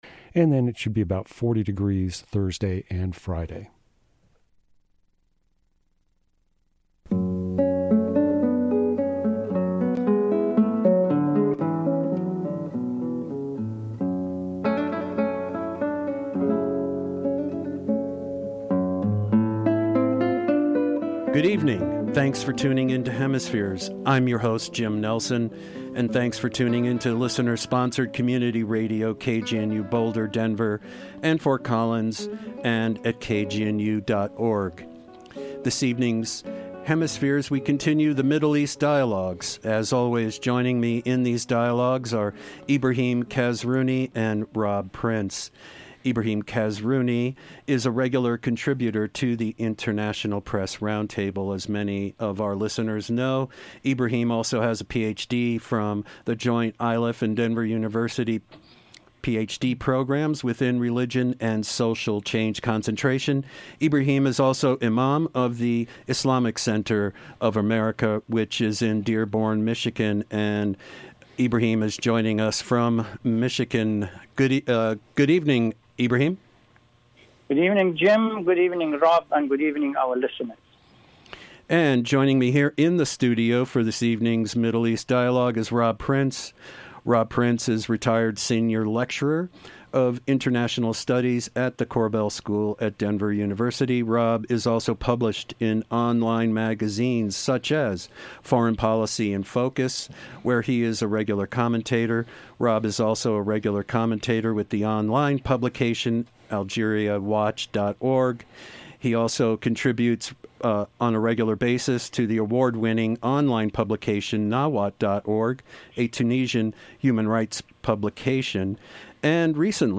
A second segment, pre-recorded a few days prior to New Years’ Eve, aired on December 31.